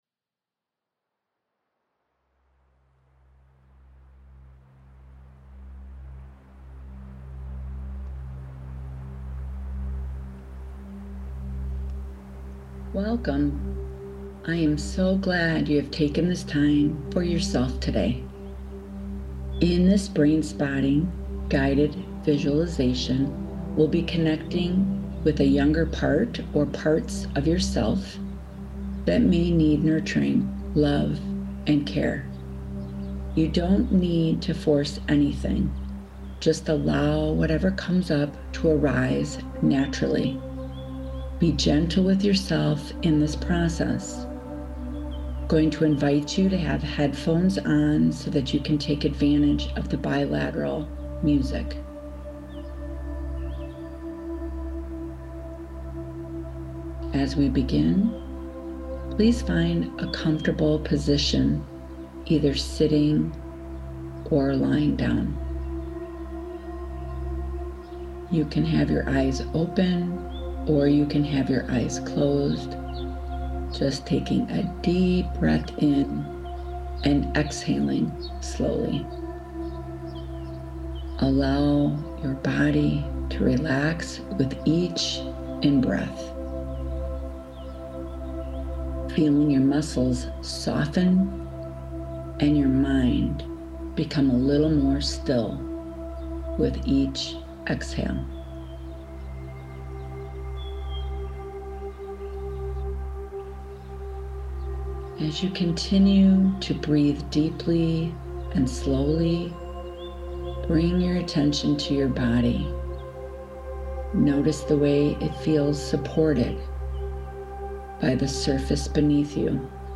Download Your Free Guided Meditation (37 MB MP3)